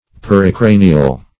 \Per`i*cra"ni*al\